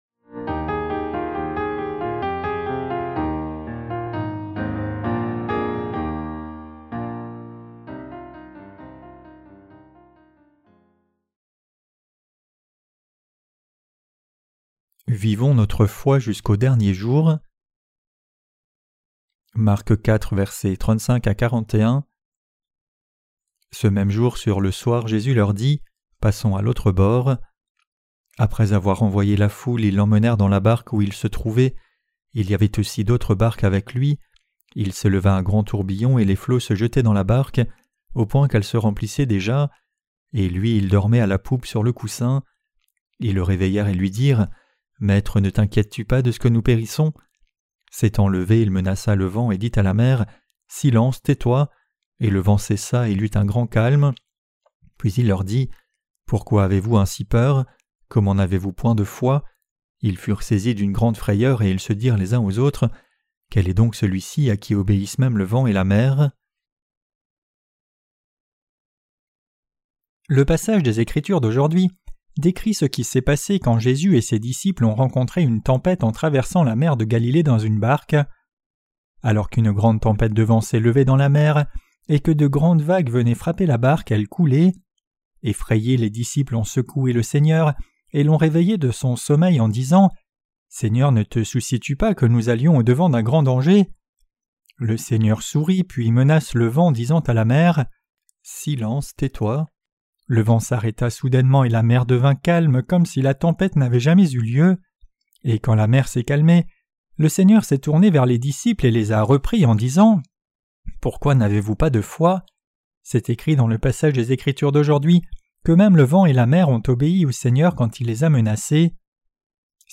Sermons sur l’Evangile de Marc (Ⅰ) - QUE DEVRIONS-NOUS NOUS EFFORCER DE CROIRE ET PRÊCHER? 11.